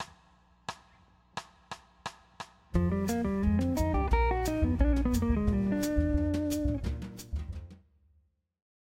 Next examples will consist of runs starting from II, going to V7(alt) using the shifted position to create the altered sound.
In this particular example I am finishing the line with a very idiomatic bebop motif.